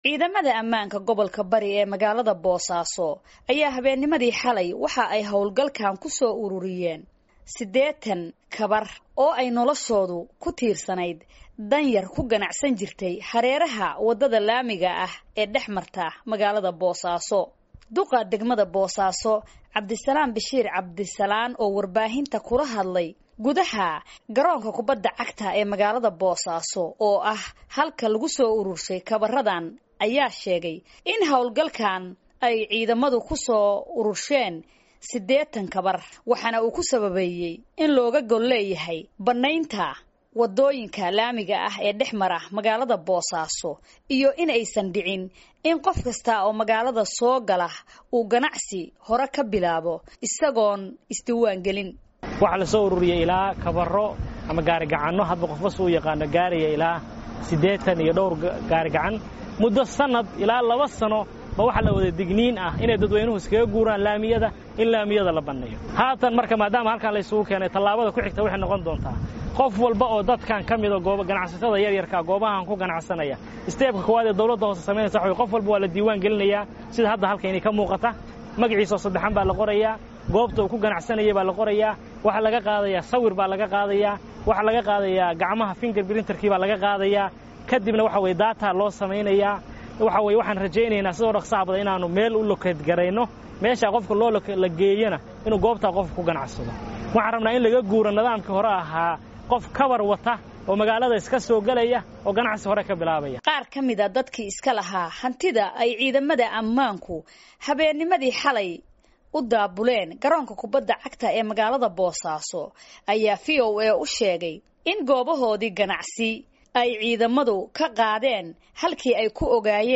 warbixintan noo soo dirtay.